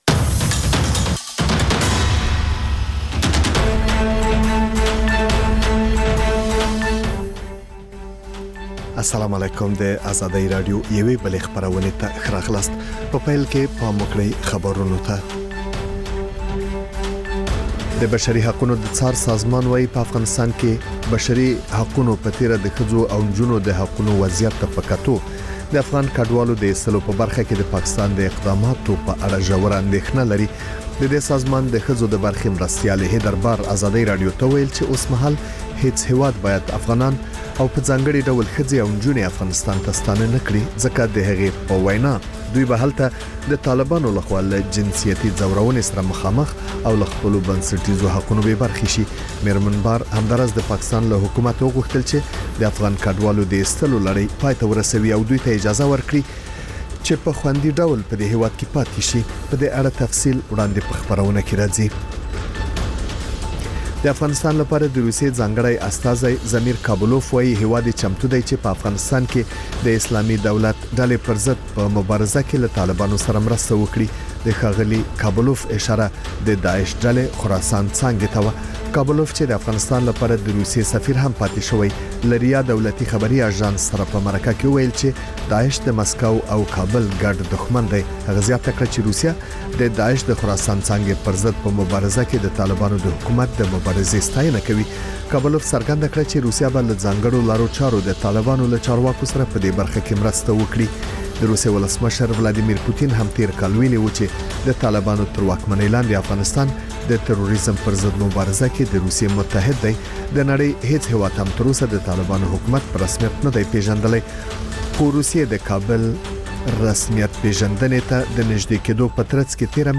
خبرونه او راپورونه